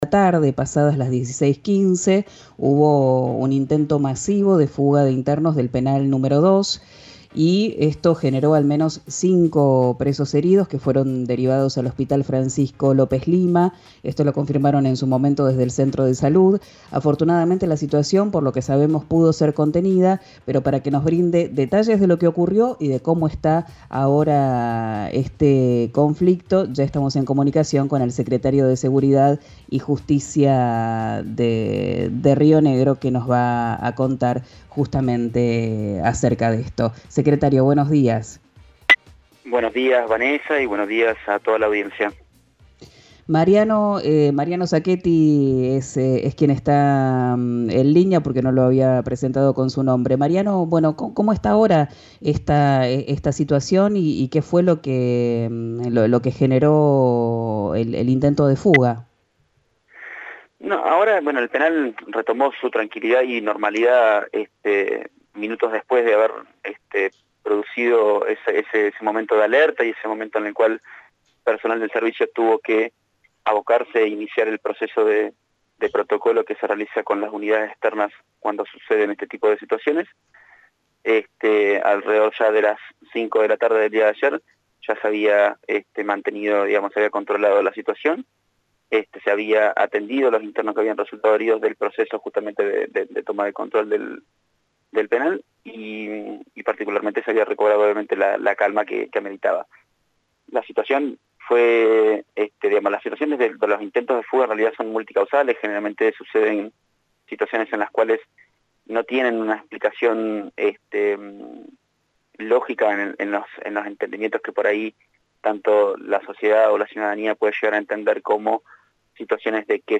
El funcionario dialogo con el programa «Ya es tiempo» que se transmite RÍO NEGRO RADIO y contó que unos 10 internos fueron atendidos en la guardia médica tras el enfrentamiento con los penitenciarios.